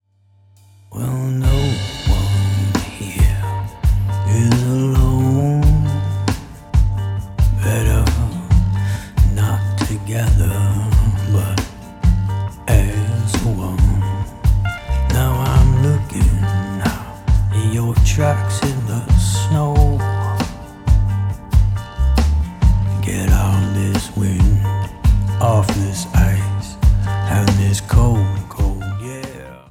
Canadian Singer-Songwriter
GENRE : Americana